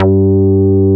P MOOG G3MF.wav